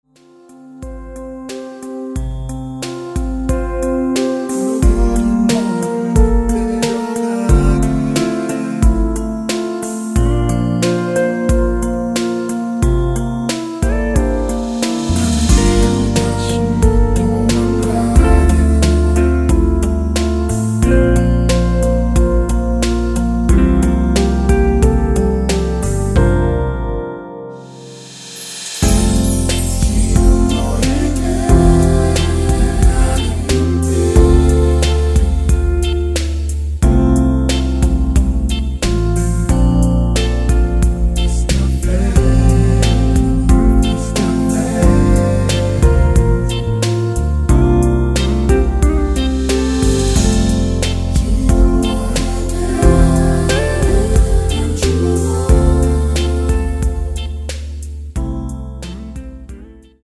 (-2)내린 코러스 포함된 MR 입니다.(미리듣기 참조)
앞부분30초, 뒷부분30초씩 편집해서 올려 드리고 있습니다.